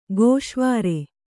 ♪ gōśvāre